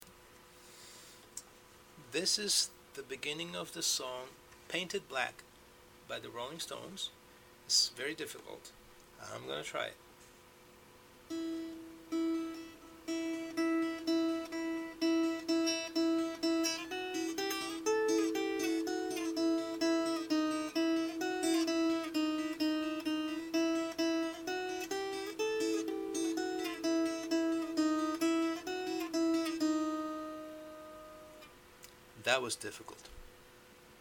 guitar beginner practice